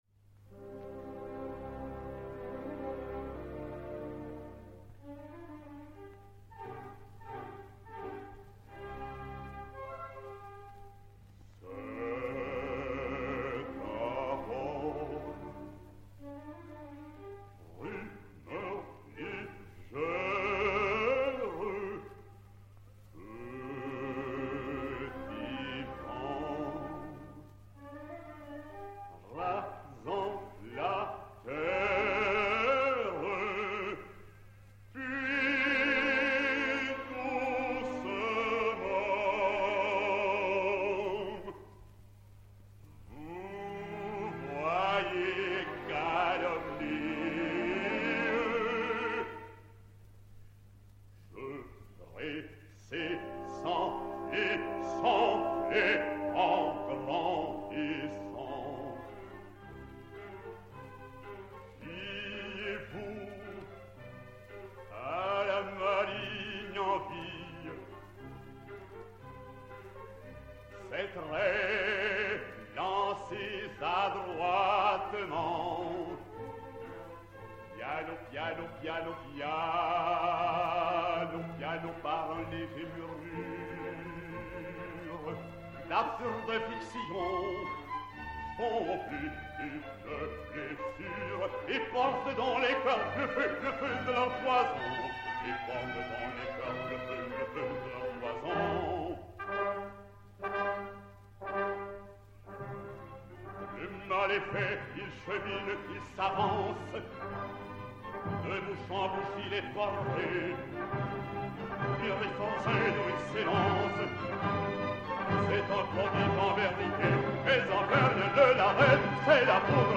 basse française
Il débuta en 1948 à l'Opéra et à l'Opéra-Comique et devait pendant vingt ans sur ces deux scènes y faire vibrer sa voix de basse chantante du métal le plus scintillant, par son grave noir et son aigu de baryton d'une solidité de roc.
Orchestre